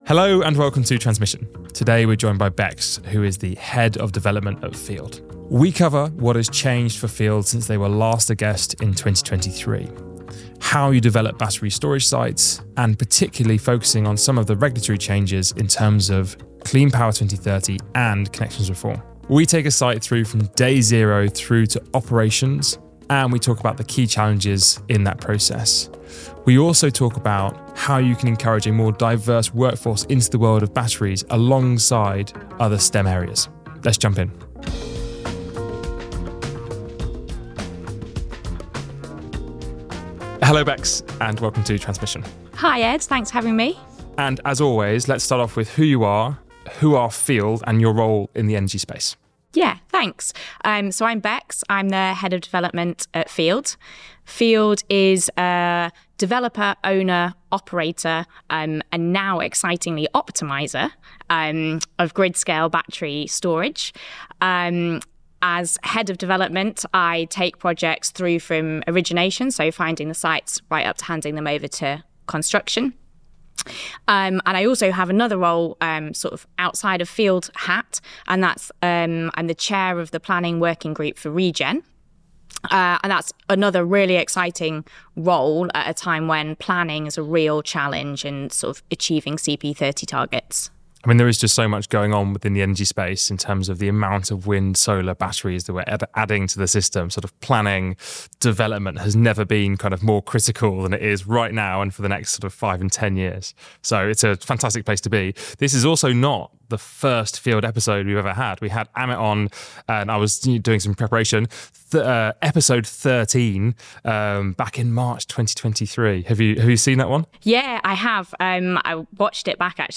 All of our interviews are available to watch or listen to on the Modo Energy site.